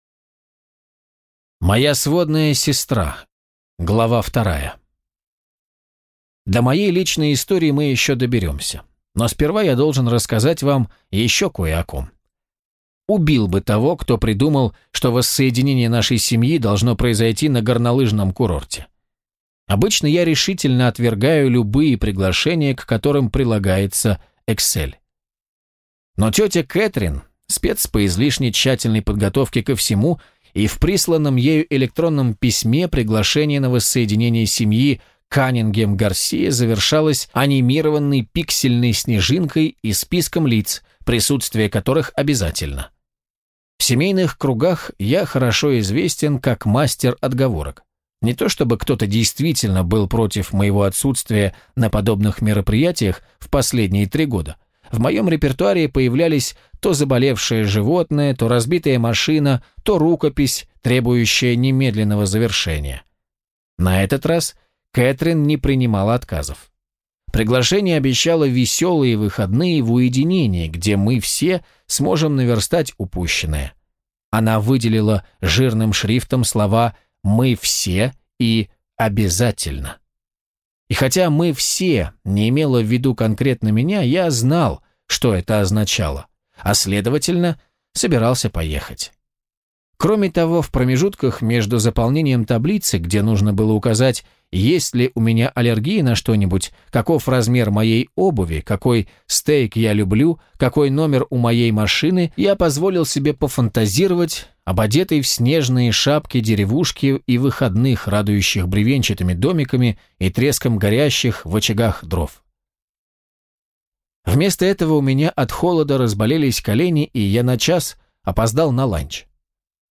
Аудиокнига Каждый в нашей семье кого-нибудь да убил | Библиотека аудиокниг